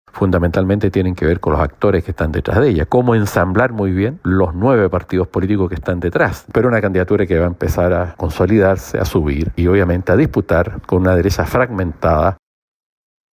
En ese sentido, el diputado y miembro del comando, Jaime Mulet (FRVS), sostuvo que los riesgos para la candidatura de Jara recaen en los actores detrás de ella.